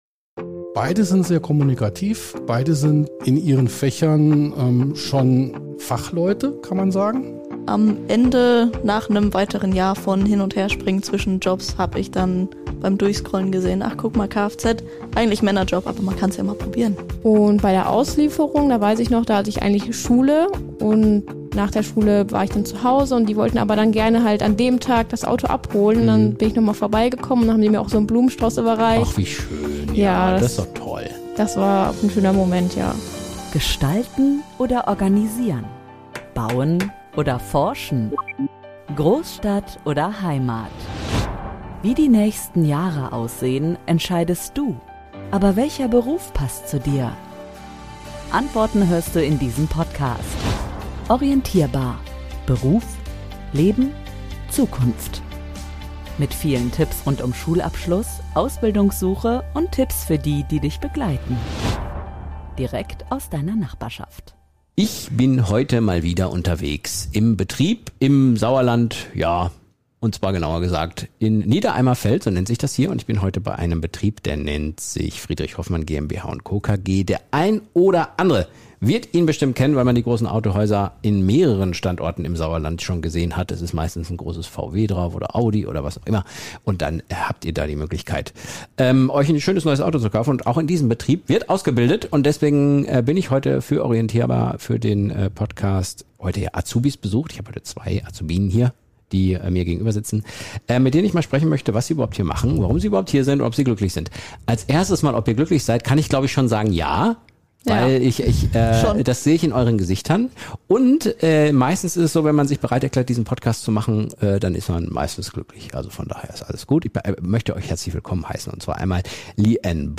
Zwei unterschiedliche Mädels mit ganz unterschiedlichen Berufen und trotzdem haben sie eines gemeinsam: Sie sind schon jetzt Experten auf ihrem Gebiet.